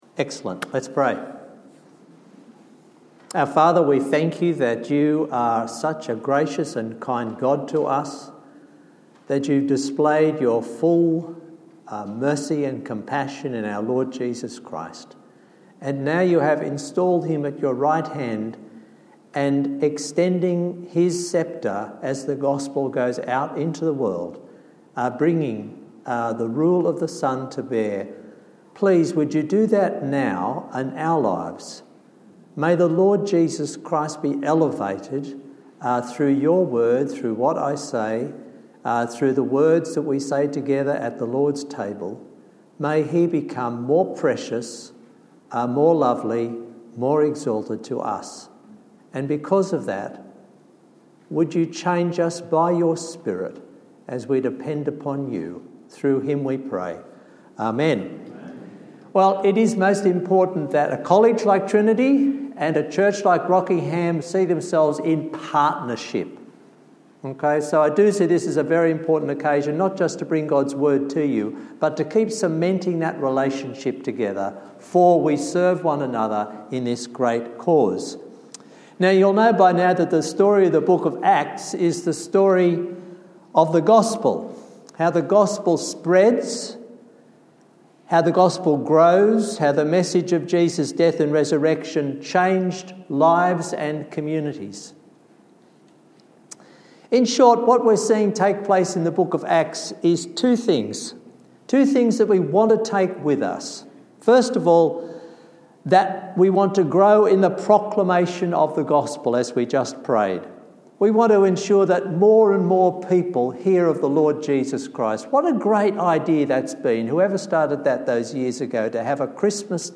To the Ends of the Earth Passage: Acts 15:1 - 16:5 Service Type: Sunday morning service Topics